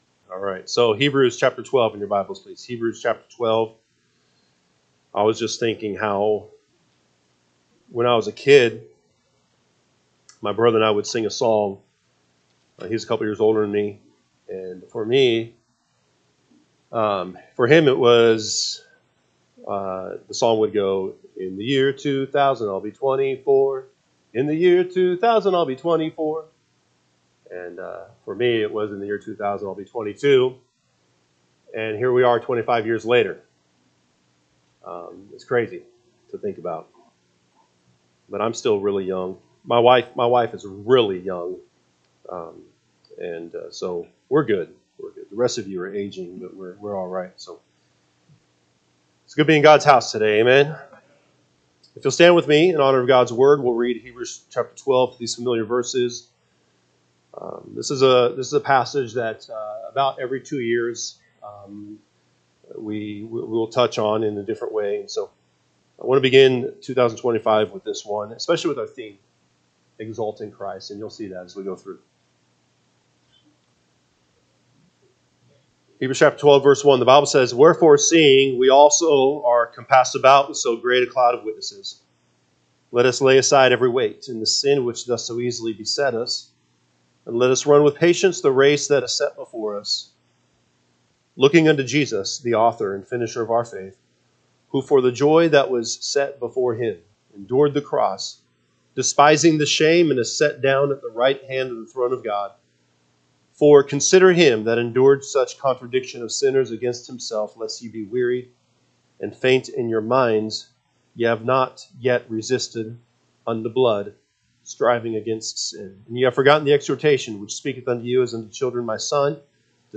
January 12, 2025 am Service Hebrews 12:1-6 (KJB) 12 Wherefore seeing we also are compassed about with so great a cloud of witnesses, let us lay aside every weight, and the sin which doth so ea…